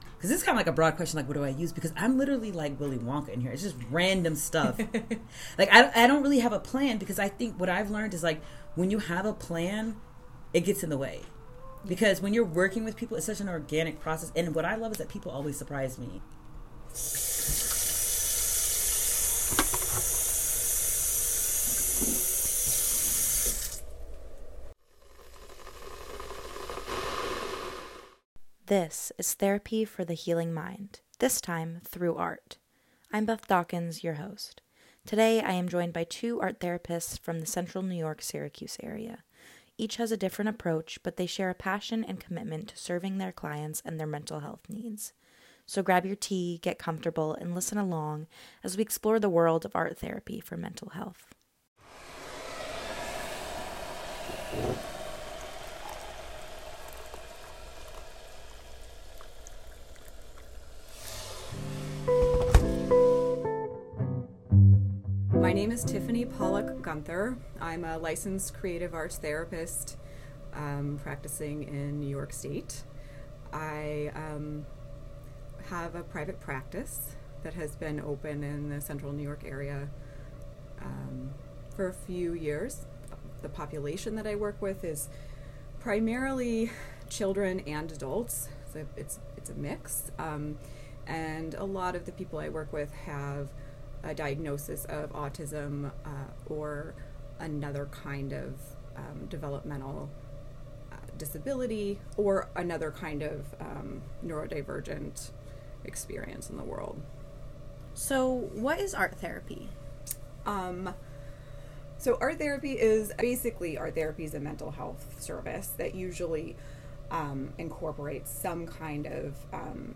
Journalism